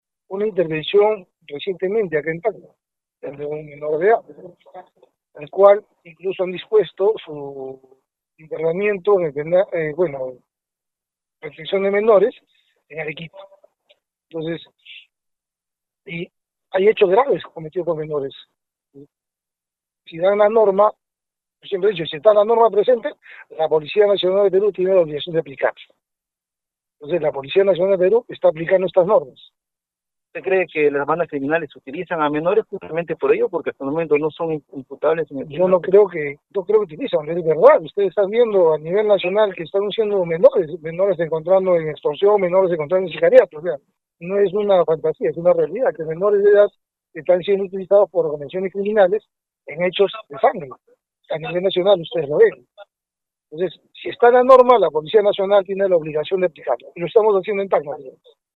De igual forma, al ser consultado sobre el tema, el general PNP Arturo Valverde, enfatizó que si la ley ha sido aprobada la Policía está en obligación de cumplirla.